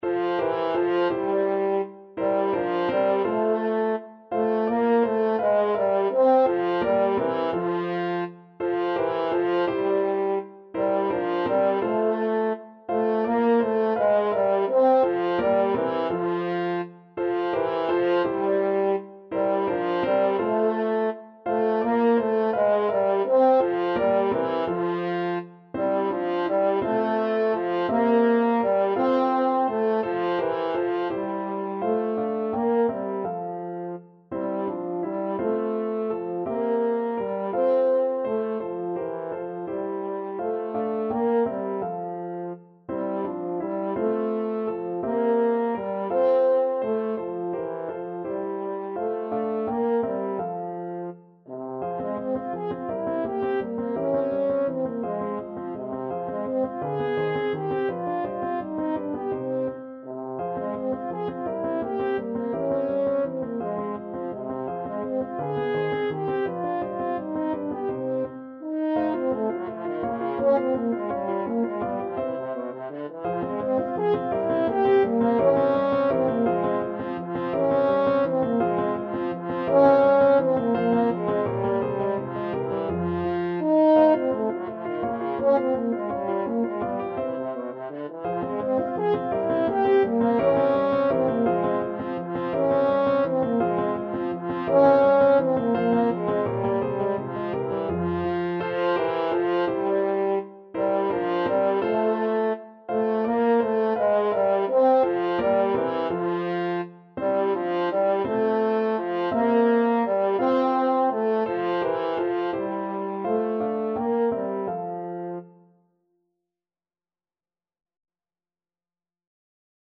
Lustig (Happy) .=56
3/8 (View more 3/8 Music)
Classical (View more Classical French Horn Music)